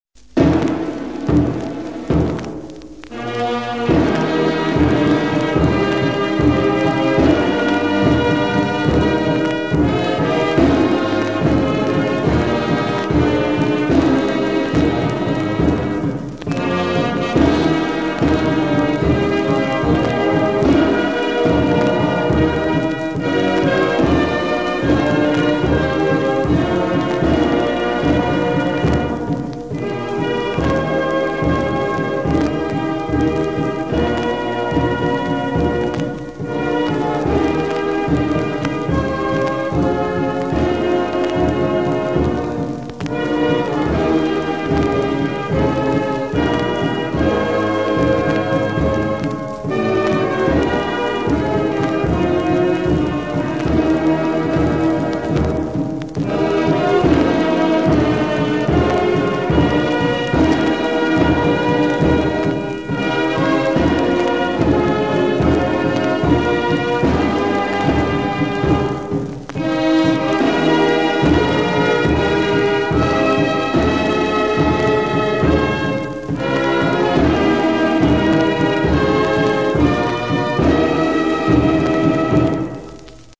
"Die Stem van Suid-Afrika" performed by the South African Air Force Band.flac